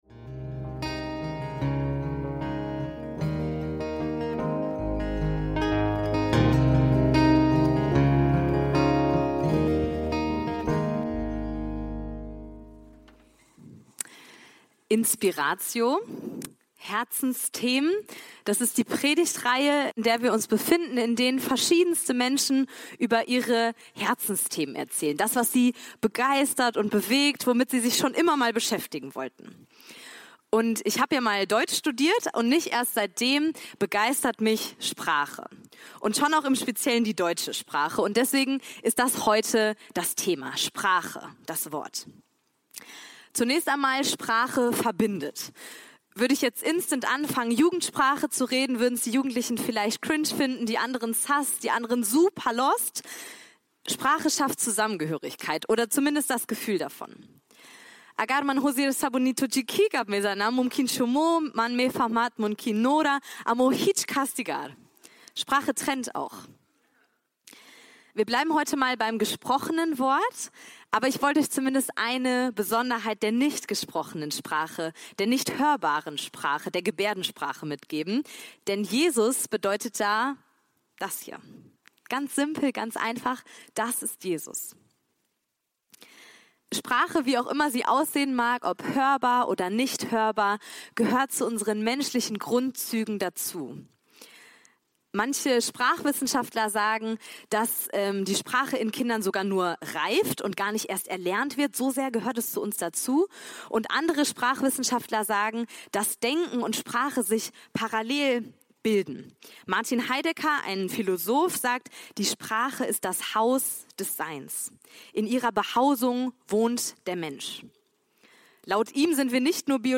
– Predigt vom 20.07.2025 ~ FeG Bochum Predigt Podcast